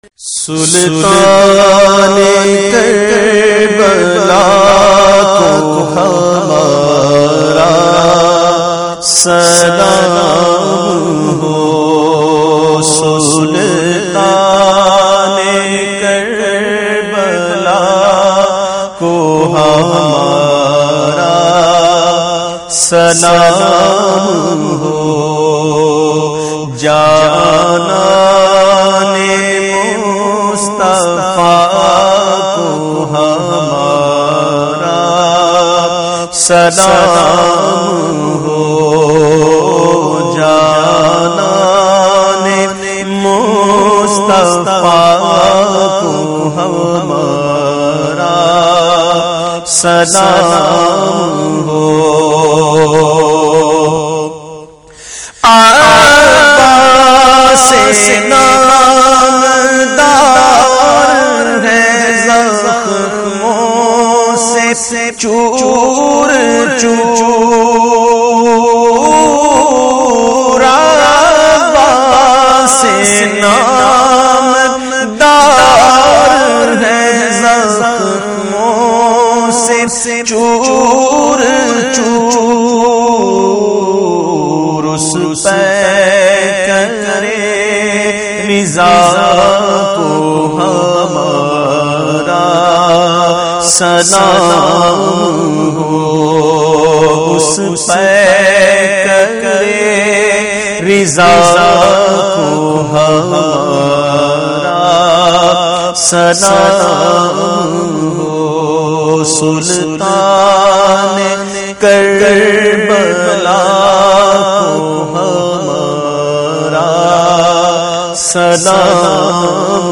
This Manqabat Related to Imam-e-Hussain
منقبت